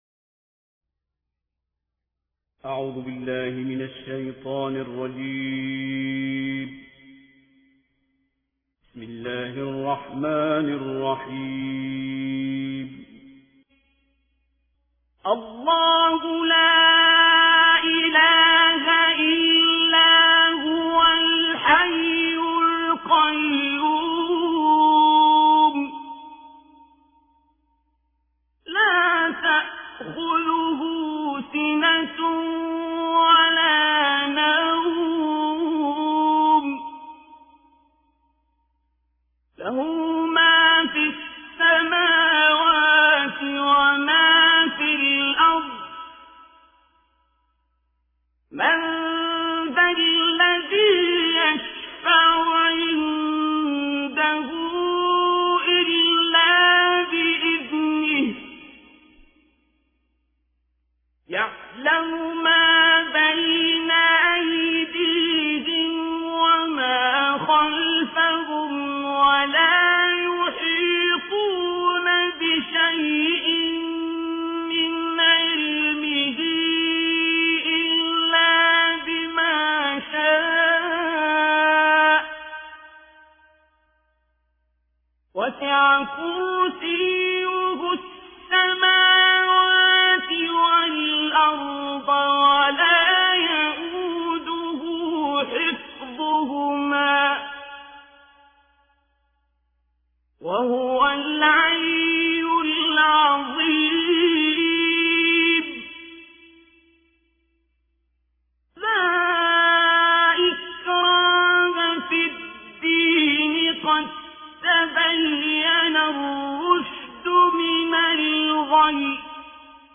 ایه الکرسی با صدای عبدالباسط
تلاوت ایه الکرسی